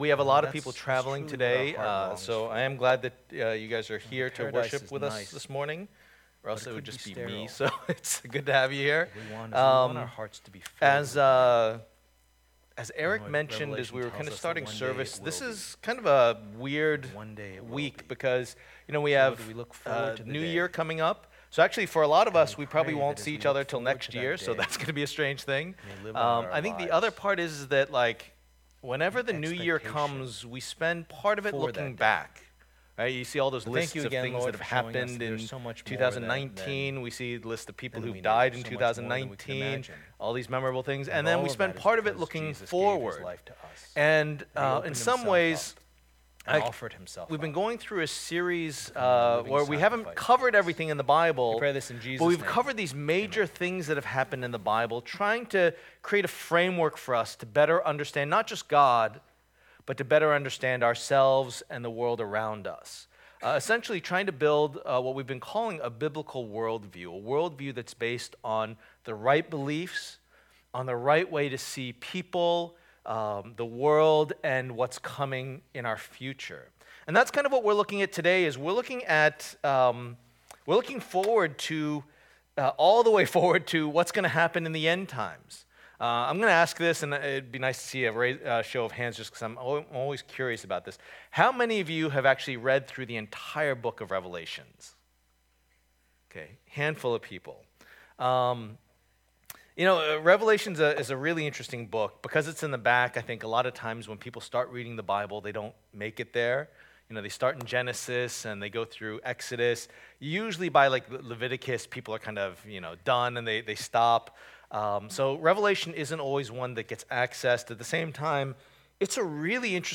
Building a Biblical View Passage: Revelation 19:1-10 Service Type: Lord's Day